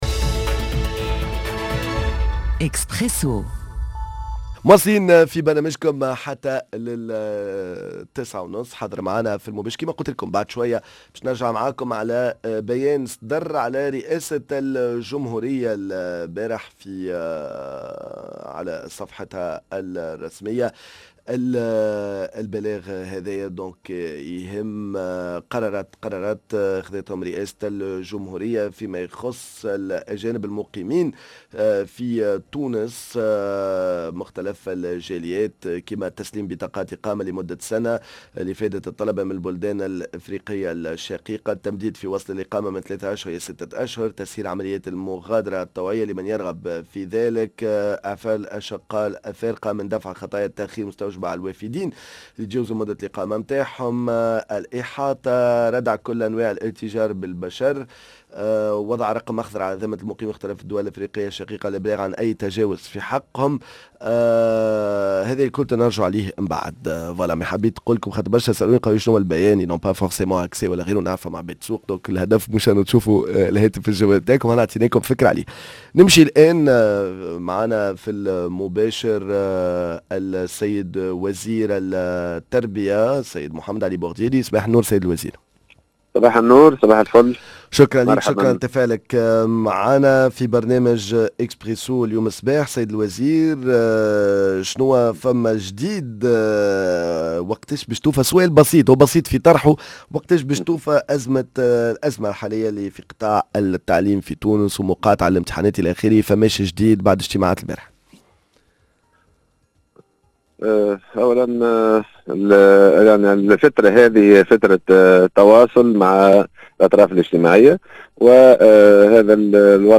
# L_interview وزير التربية و التعليم محمد علي البوغديري متى تنتهي أزمة قطاع التعليم في تونس؟؟!!